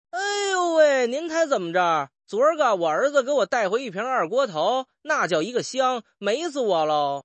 描述：北京胡同里长大的少年。
支持的语种/方言：中文（北京话）